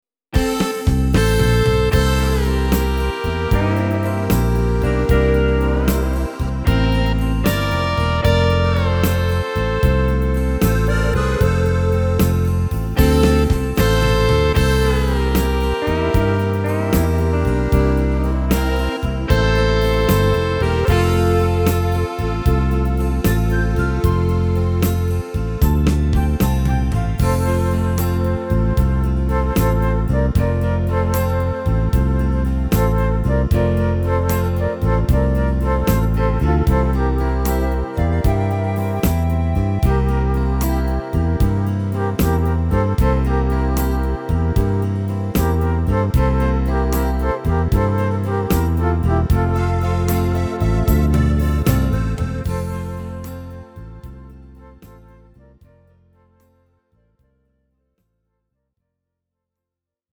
Tempo: 76 / Tonart: F – Dur